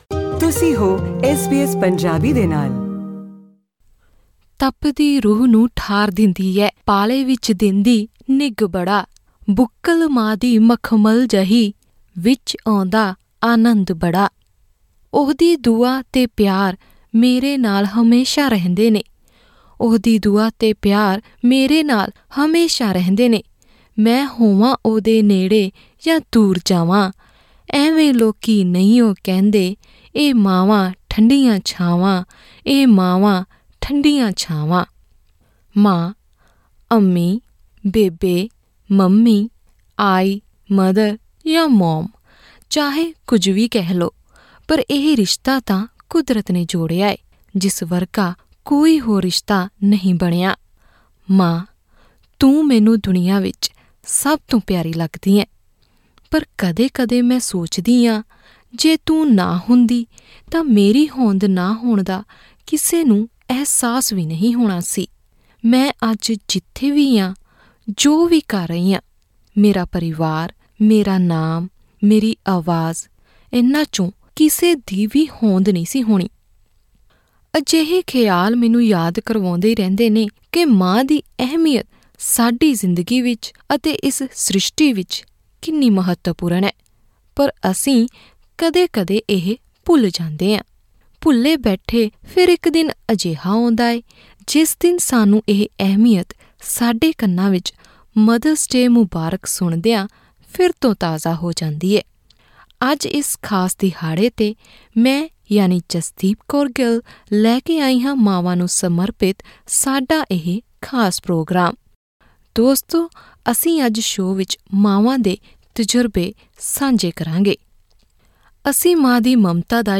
Here we speak to some mothers who share their experiences of sacrifice, unconditional love and joys of motherhood.
Click on the audio player at the top of the page to listen to this conversation in Punjabi.